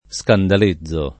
scandalizzo [ S kandal &zz o ]